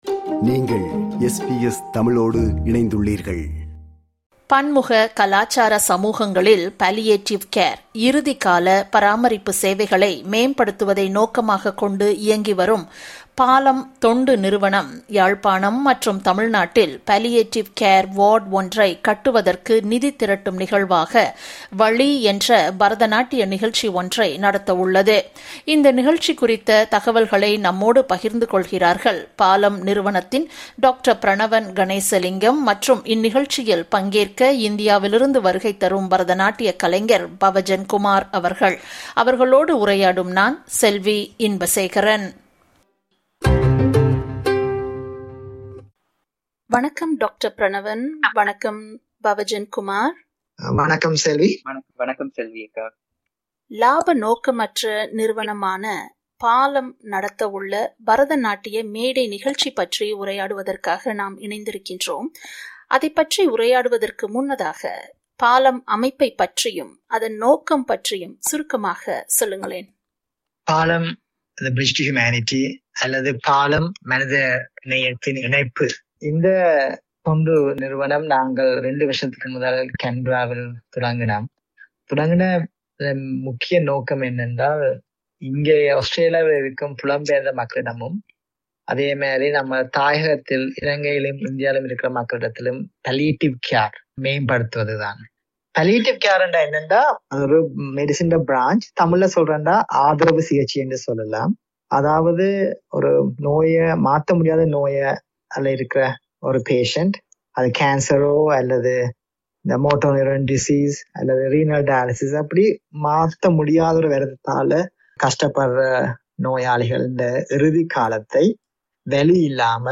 அவர்களோடு உரையாடுகிறார்